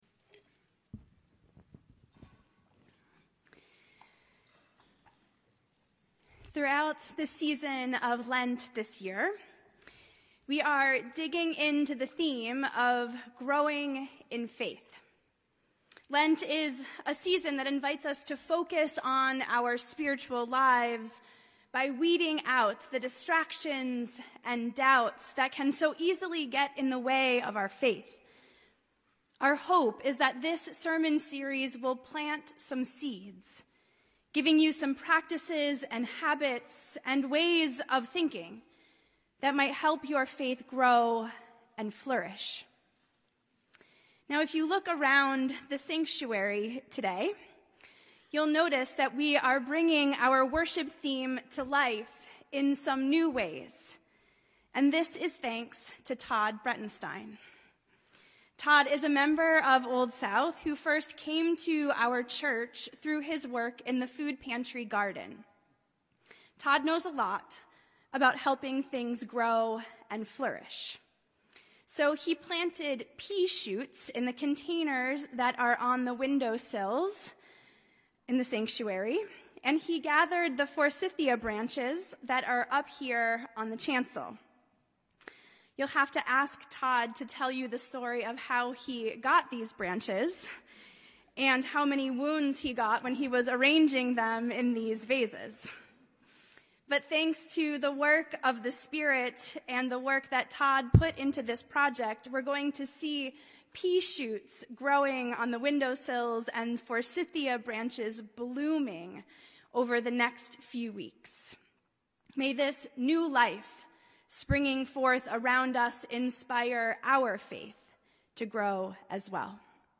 Sermons - Old South Union Church – Weymouth